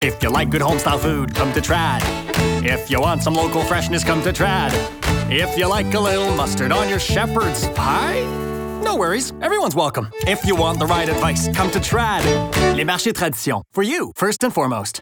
Publicité (Marchés Tradition) - ANG